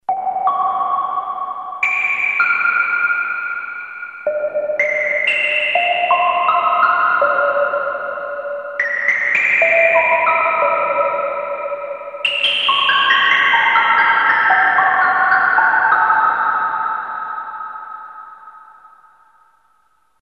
Digital Reverb (1985?)
echo drops with ELKA SYNTHEX